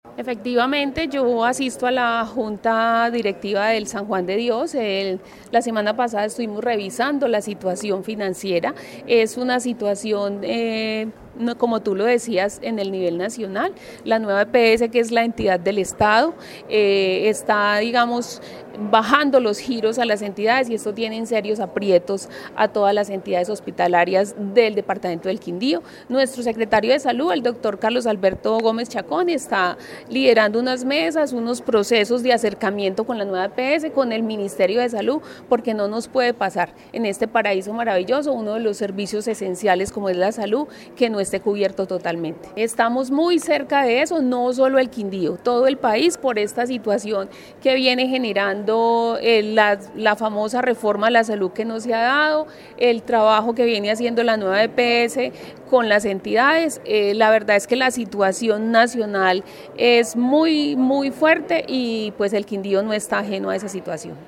Gobernadora encargada del Quindío